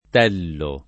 [ t $ llo ]